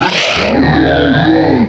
pokeemerald / sound / direct_sound_samples / cries / probopass.aif